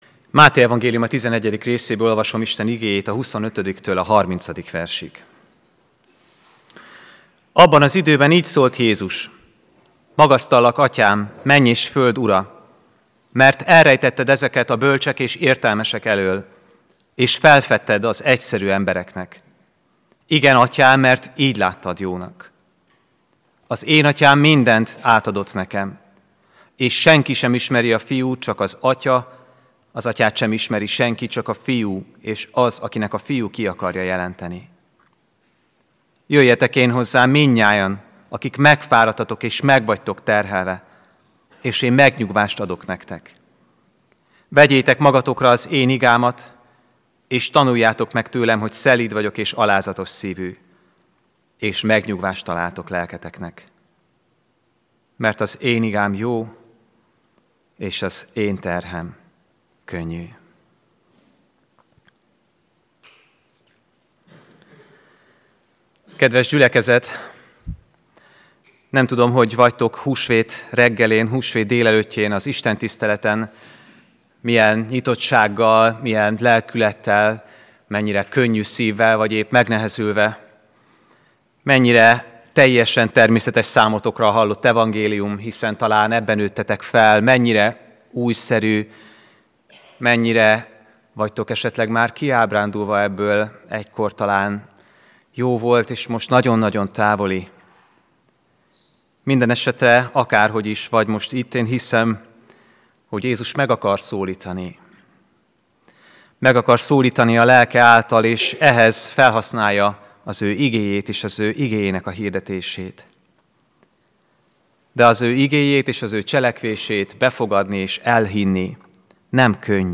AZ IGEHIRDETÉS LETÖLTÉSE PDF FÁJLKÉNT AZ IGEHIRDETÉS MEGHALLGATÁSA
Húsvét vasárnap